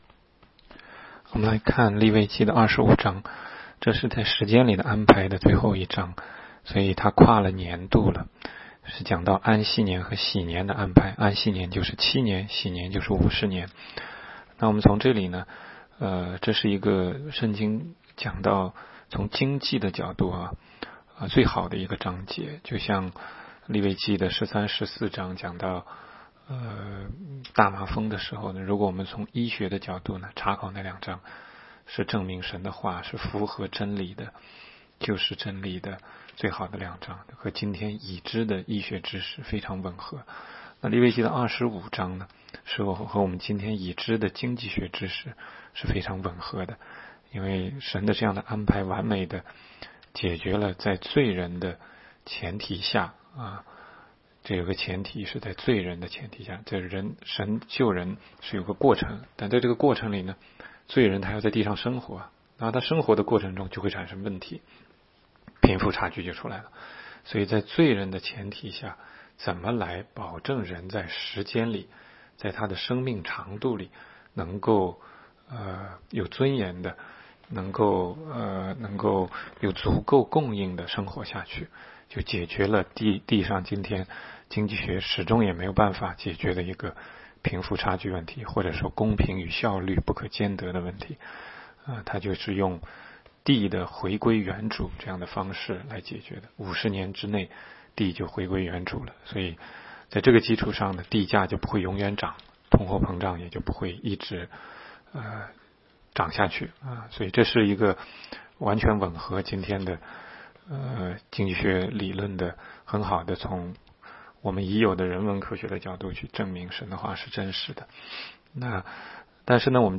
16街讲道录音 - 每日读经-《利未记》25章
每日读经